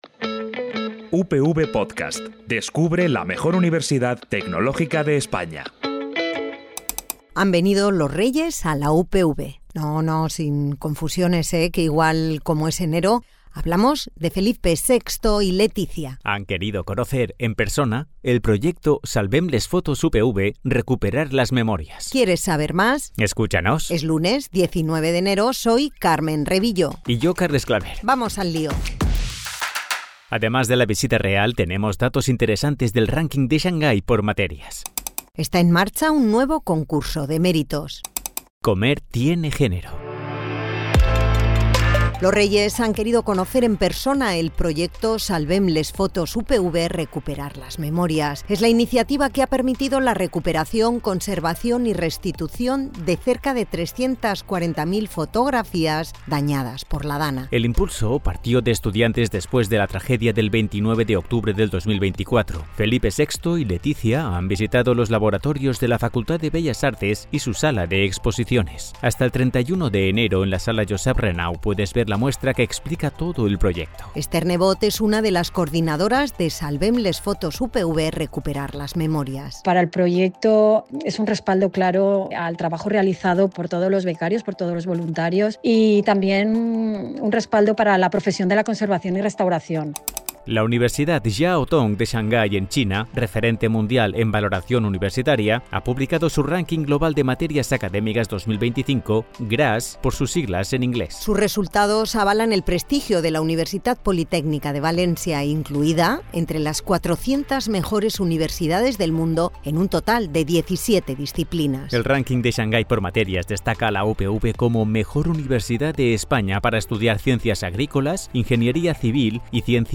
Es la versión sonora del Boletín Informativo para informarte de lo que pasa en la Universitat Politècnica de València.